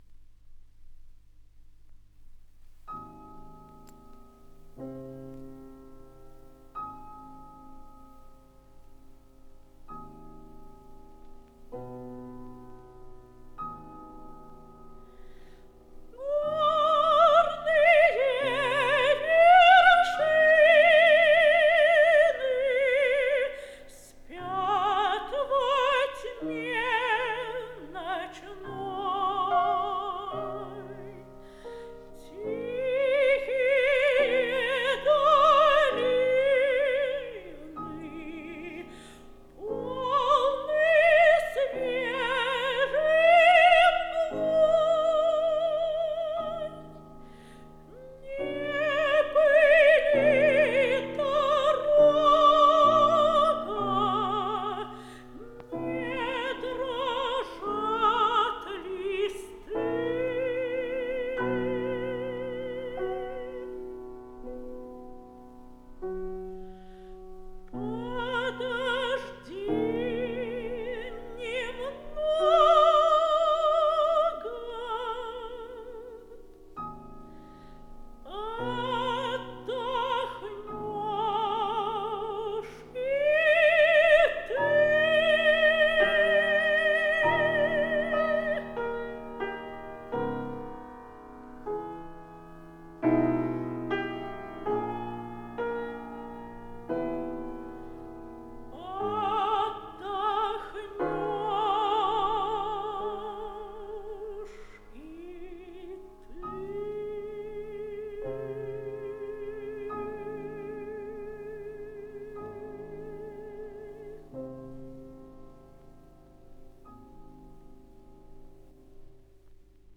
Наталья Шпиллер
ф-но) - Горные вершины